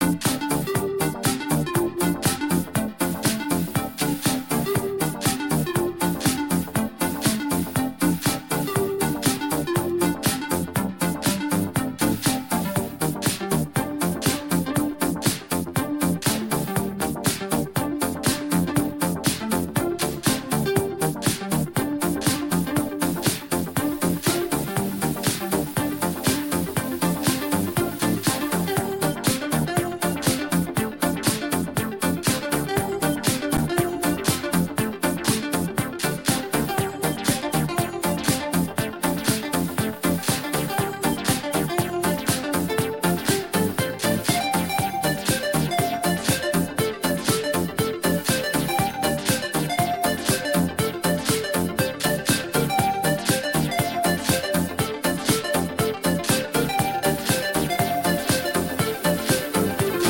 electro fun